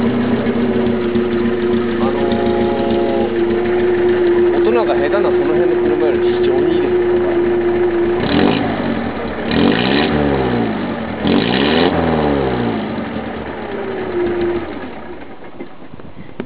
こちら（360KB）　　空ぶかしの音は